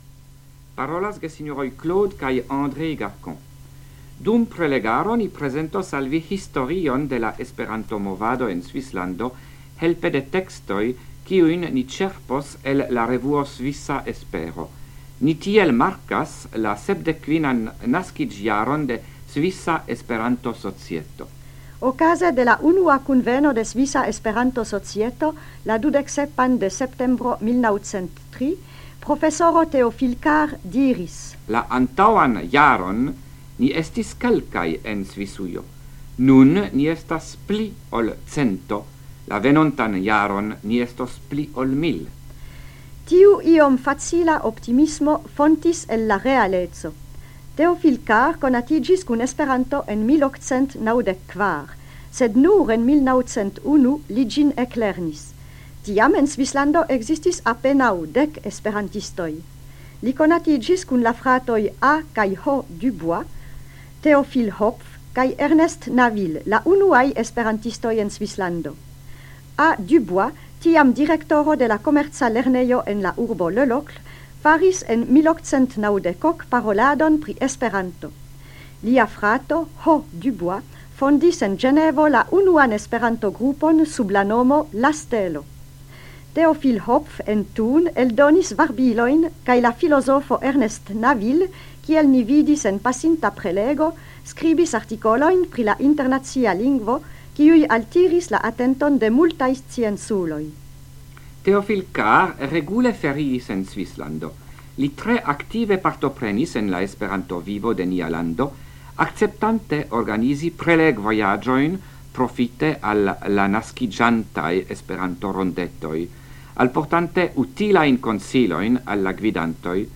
Radioprelegoj en la jaro 1978